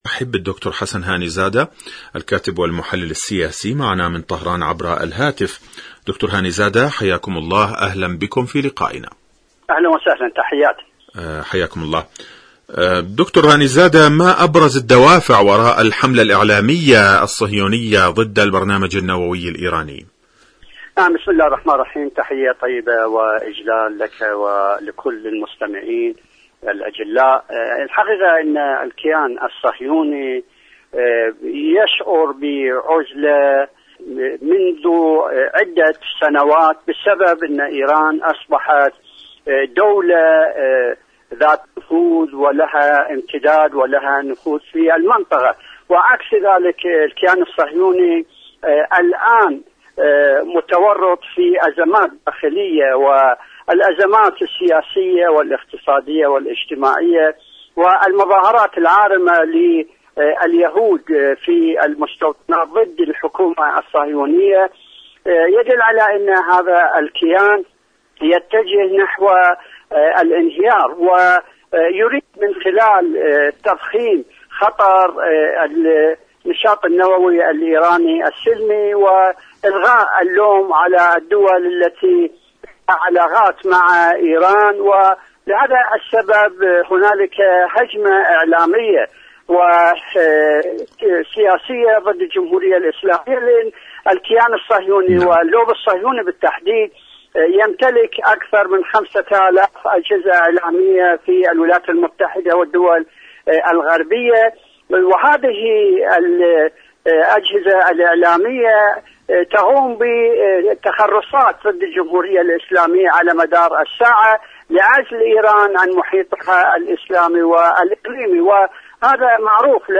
مقابلات